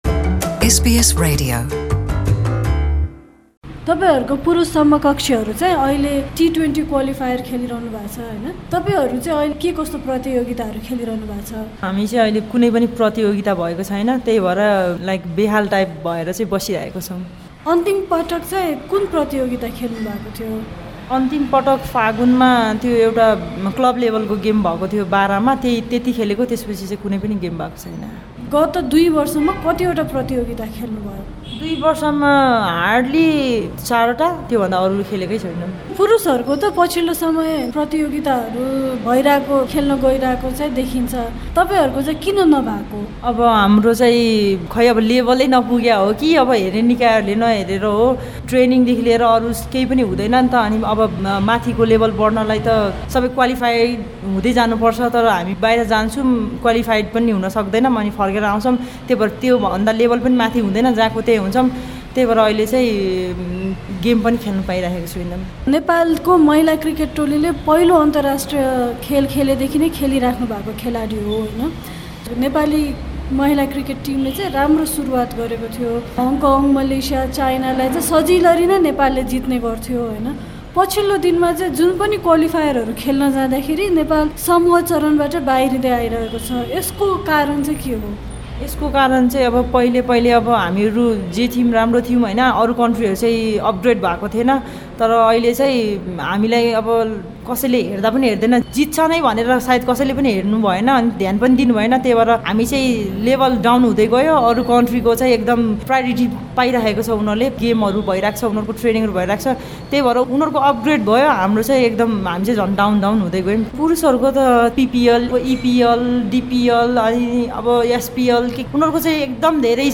एसबीएस नेपालीसँगको कुराकानीमा